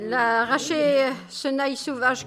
Localisation Île-d'Olonne (L')
Catégorie Locution